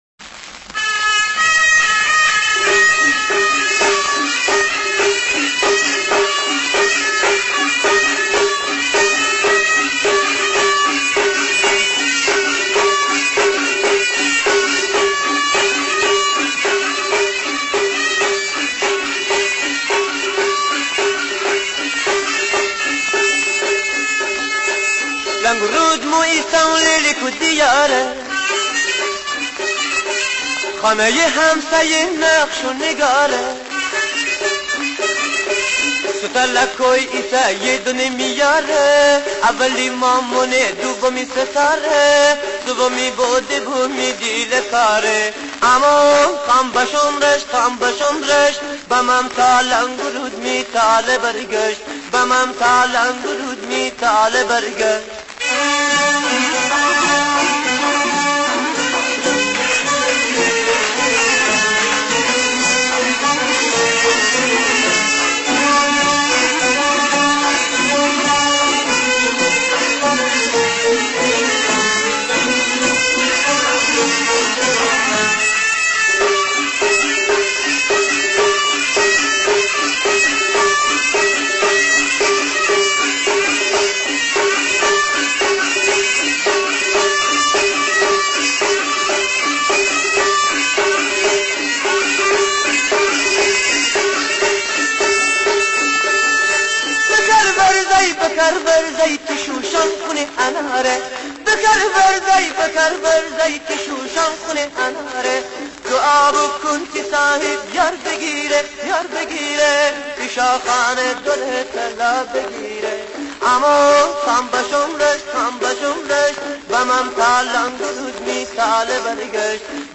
اهنگ شاد و پرانرژی گیلانی